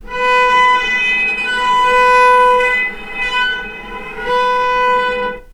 healing-soundscapes/Sound Banks/HSS_OP_Pack/Strings/cello/sul-ponticello/vc_sp-B4-mf.AIF at c8d0b62ab1b5b9a05c3925d3efb84e49ca54a7b3
vc_sp-B4-mf.AIF